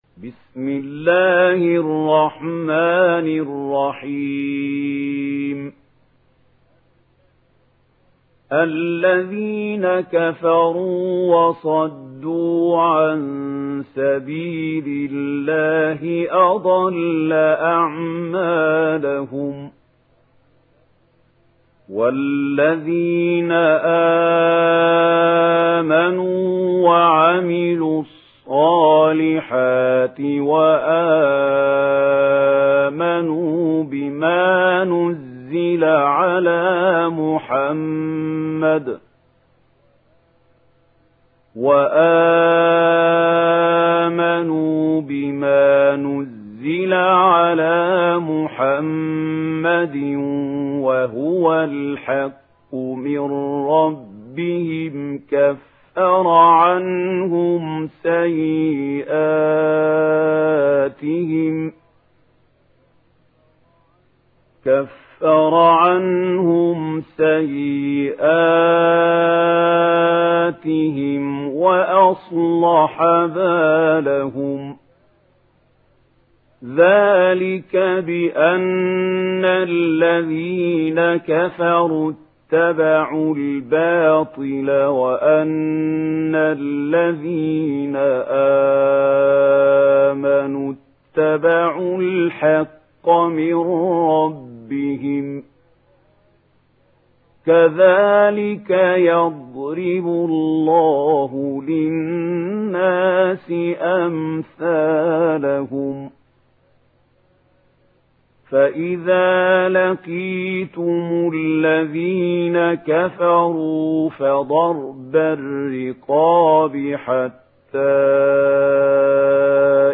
دانلود سوره محمد mp3 محمود خليل الحصري روایت ورش از نافع, قرآن را دانلود کنید و گوش کن mp3 ، لینک مستقیم کامل